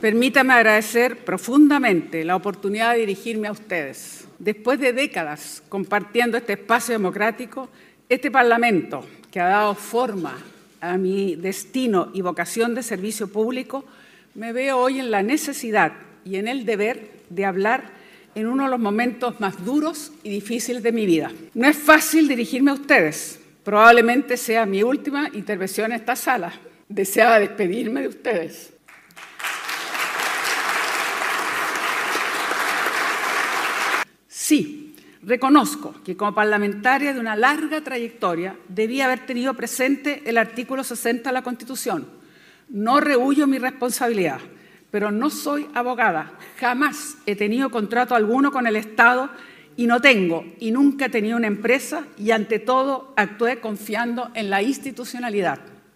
Este martes 8 de abril, la senadora PS Isabel Allende Bussi ofreció un emotivo discurso ante sus colegas del Senado, en lo que podría ser su última intervención en la sala, luego de los acuerdos alcanzados por los Comités del Senado. Visiblemente conmovida, Allende expresó su gratitud y despedida a sus pares tras una larga trayectoria en el servicio público.
La senadora comenzó su intervención con un tono reflexivo: “No es fácil dirigirme a ustedes, porque probablemente sea mi última intervención en esta sala, y deseaba despedirme de ustedes, mis colegas de tantos años, y transmitir en breves palabras toda una historia y una vida dedicada al servicio público en este Congreso Nacional”.